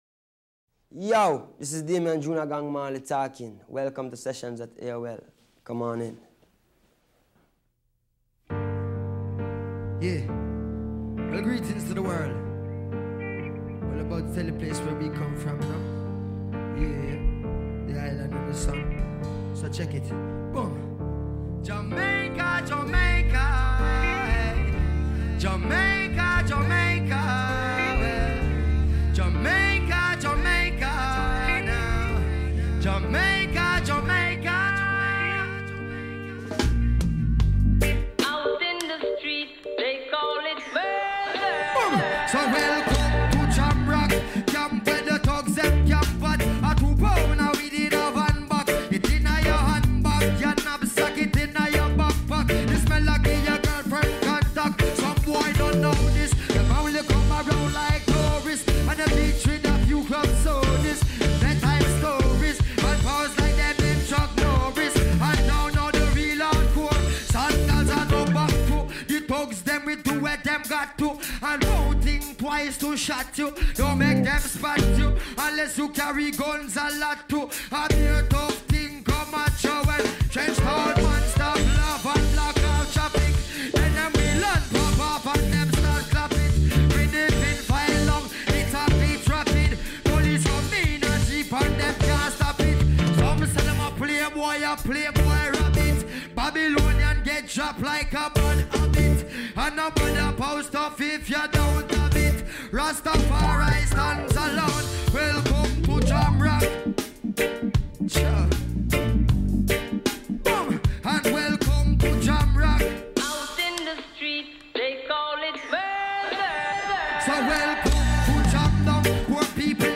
This song is gritty, grimey and 100% real